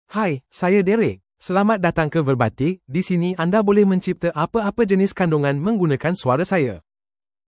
MaleMalay (Malaysia)
DerekMale Malay AI voice
Voice sample
Male
Derek delivers clear pronunciation with authentic Malaysia Malay intonation, making your content sound professionally produced.